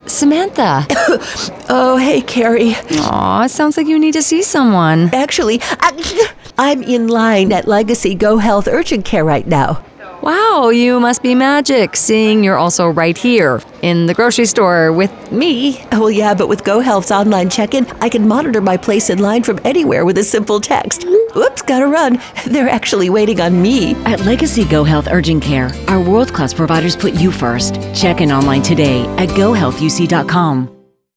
Radio: